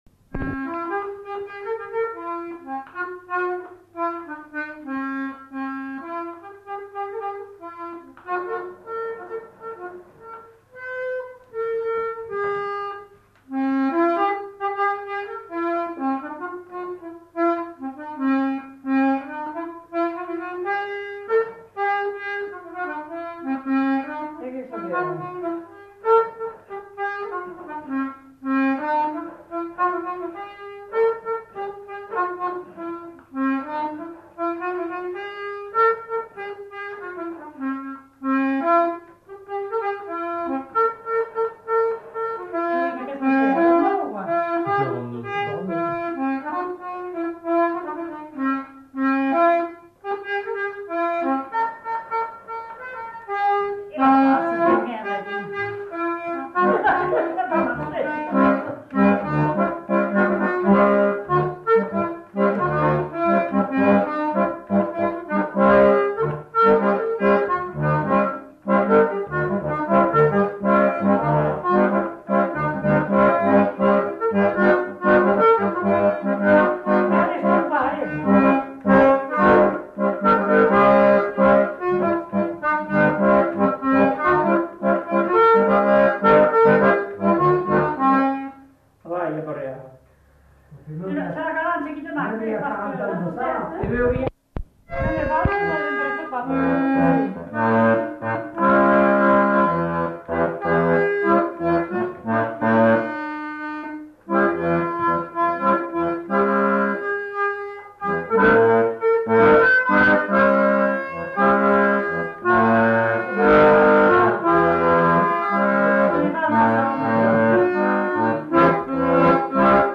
Rondeau
Aire culturelle : Néracais
Lieu : Sainte-Maure-de-Peyriac
Genre : morceau instrumental
Instrument de musique : accordéon diatonique
Danse : rondeau